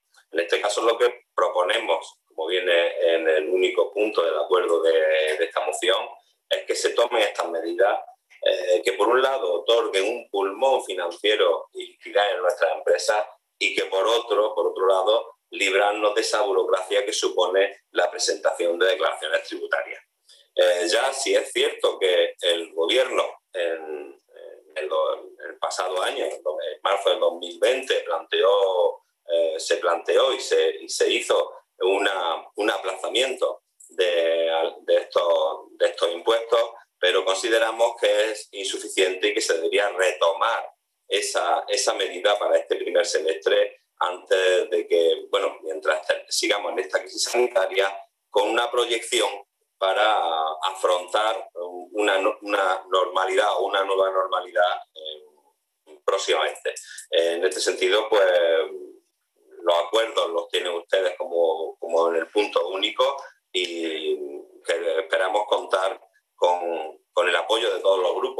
Rafael Burgos, portavoz grupo provincial Ciudadanos Almería, Pleno Telemático Diputación de Almería